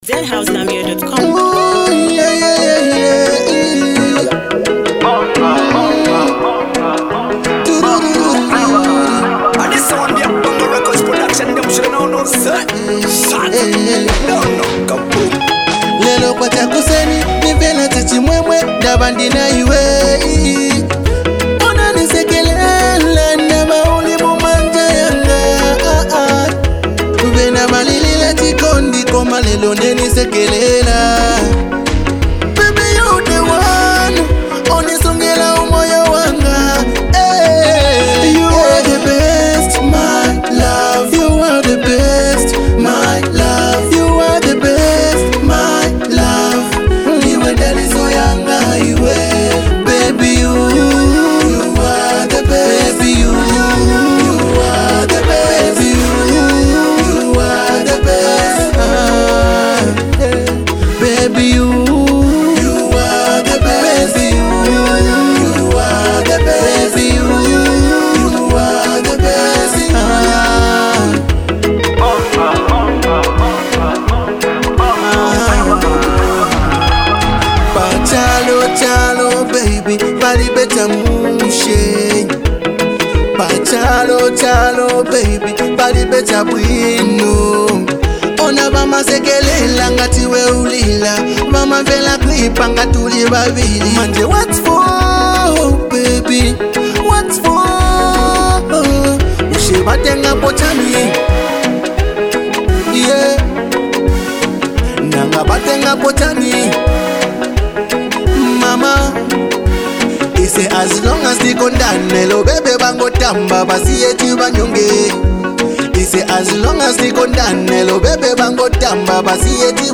With smooth vocals and soulful beats